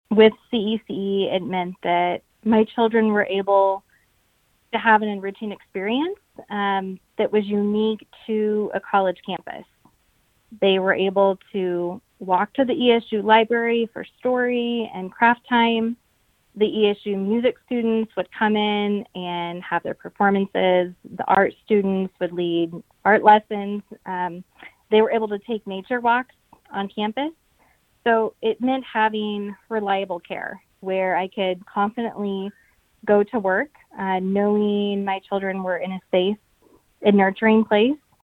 Another parent who has two children enrolled — and who asked not to be named — says the May 20 announcement has left her scrambling to find suitable options.